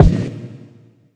live_kick_8.wav